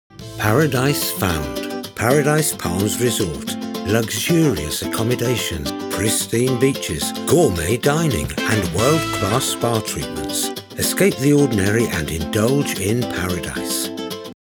My natural voice is neutral British English, clear, friendly and easy to listen to with the ability to vary the dynamics to suit the project.
My studio and recording equipment are selected and optimised to eliminate reflections and nasty audio frequencies.
Radio Commercials
Words that describe my voice are Neutral British, Clear, Expressive.